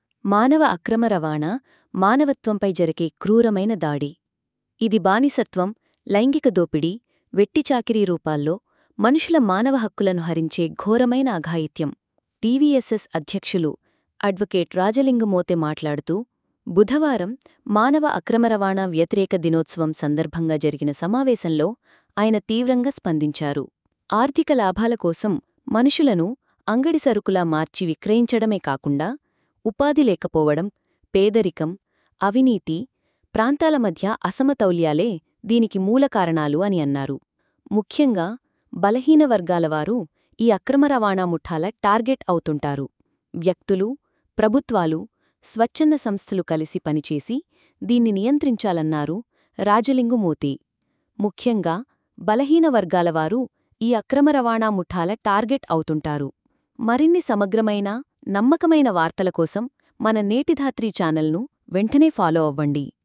బుధవారం ప్రపంచ మానవ అక్రమ రవాణా వ్యతిరేక దినోత్సవం సందర్భంగా ఏర్పాటు చేసిన సమావేశంలో ఆయన మాట్లాడారు.